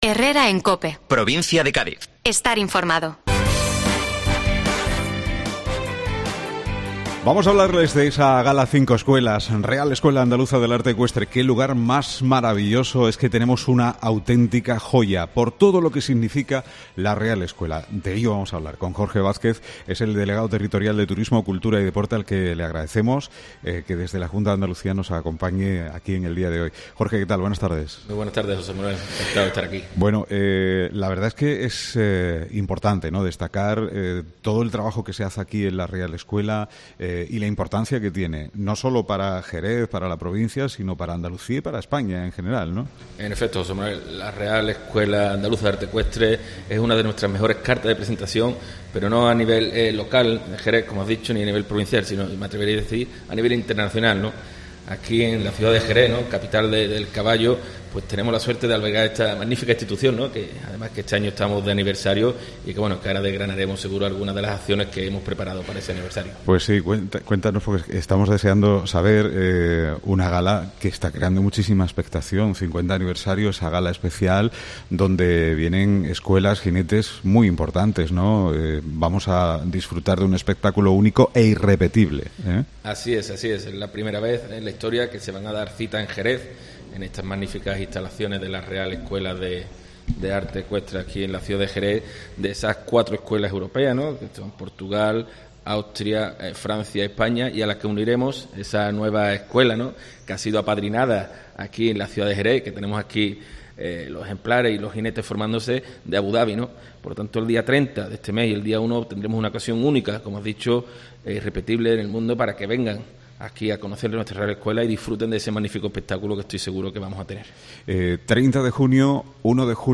Jorge Vázquez, Delegado Territorial de Turismo, Cultura y Deporte de la Junta - Gala V Escuelas Real Escuela